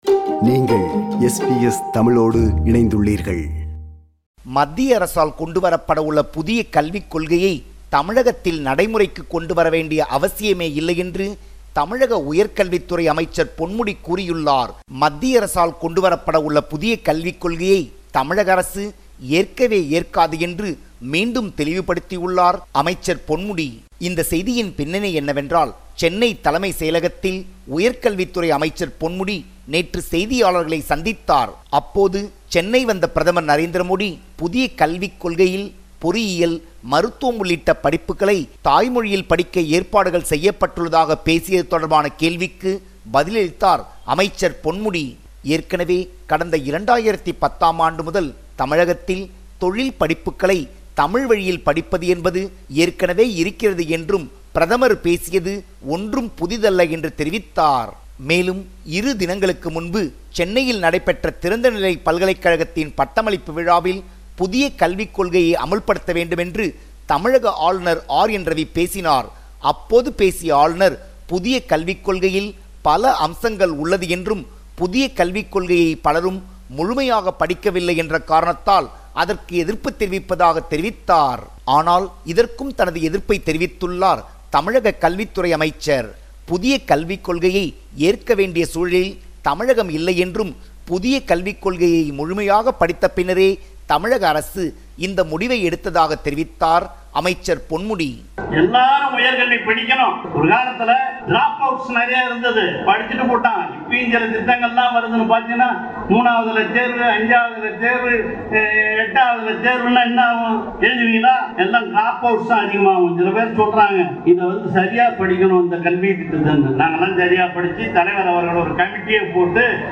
SBS Tamil